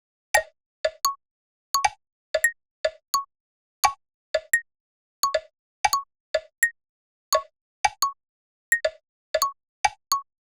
The MIDI metronome does always exactly the same but has nothing to to with the song's timing and measure.
metronomes.flac